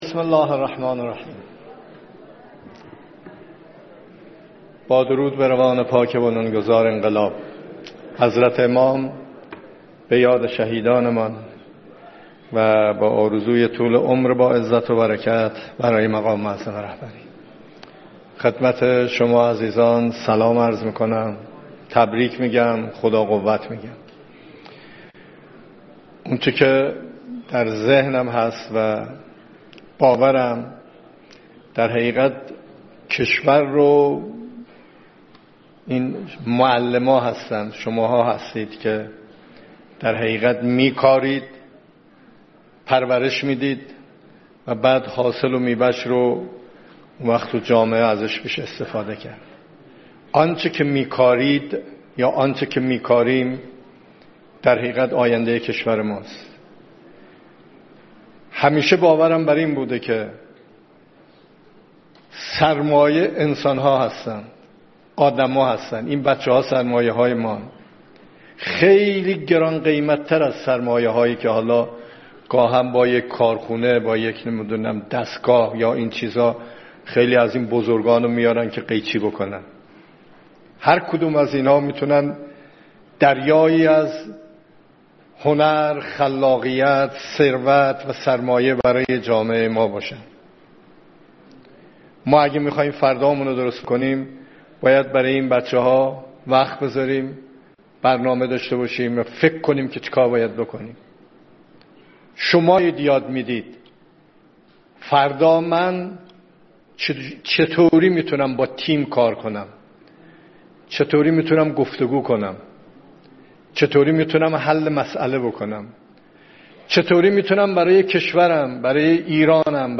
سخنان رئیس جمهور در آیین گرامیداشت روز معلم